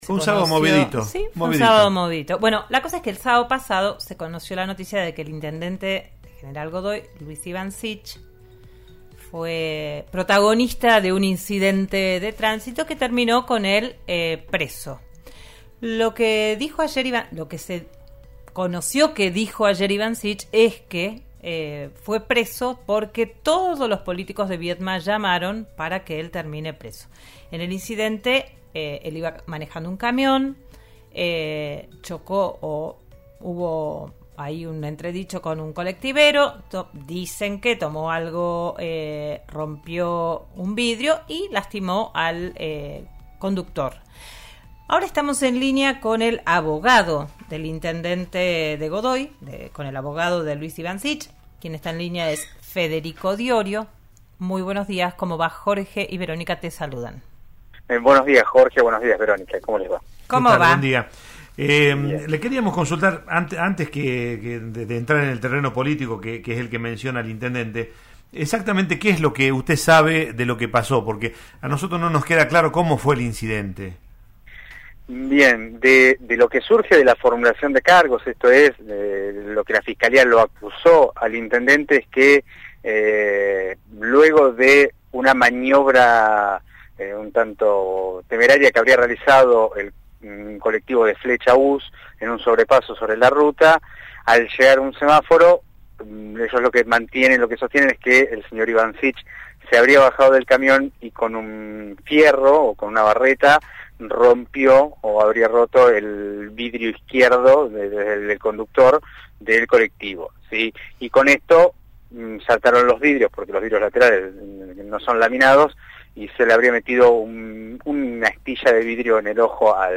en «Digan lo que Digan» por RN RADIO: